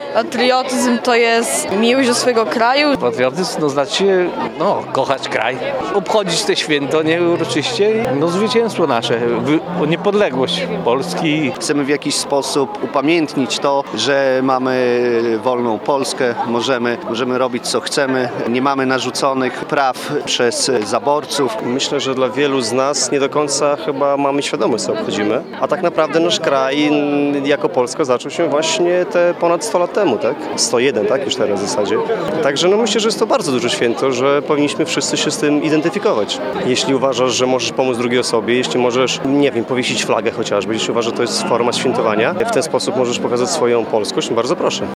– Patriotyzm można wyrażać na różne sposoby – mówili reporterowi Radia 5 uczestnicy imprezy.